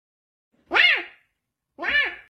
boykisser meow Meme Sound Effect
Category: Anime Soundboard
boykisser meow.mp3